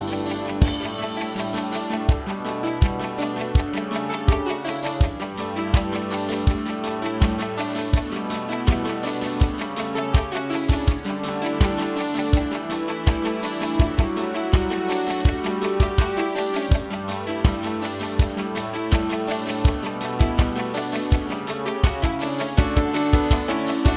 Minus Bass Pop (1980s) 4:23 Buy £1.50